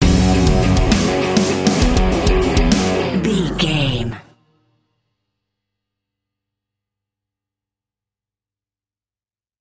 Ionian/Major
D
hard rock
lead guitar
bass
drums
aggressive
energetic
intense
nu metal
alternative metal